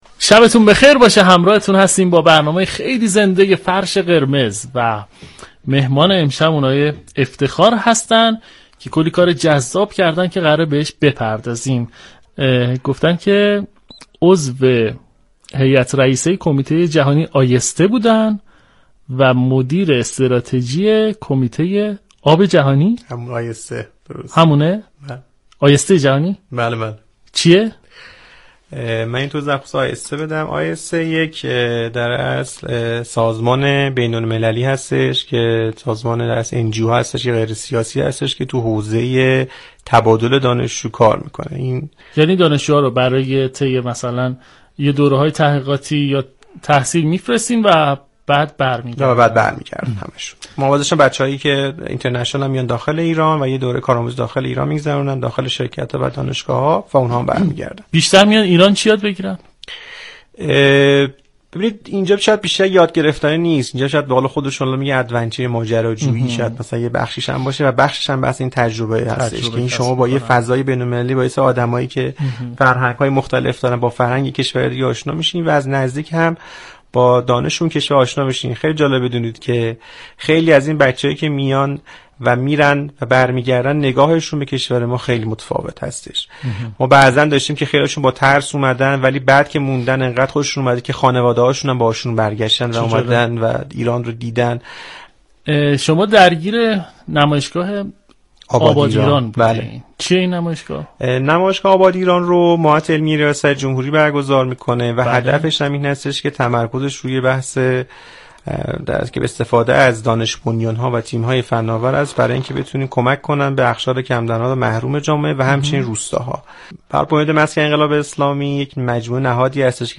در ادامه شنونده گلچین این گفتگو باشید.